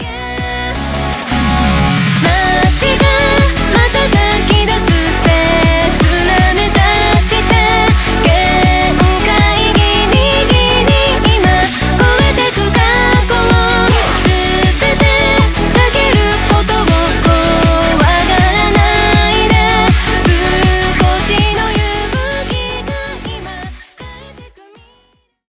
EUROBEAT